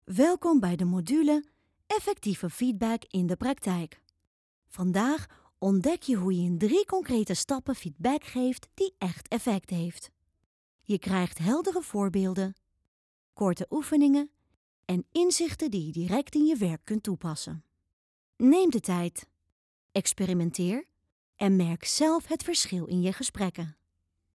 Opvallend, Speels, Veelzijdig, Vriendelijk, Warm
E-learning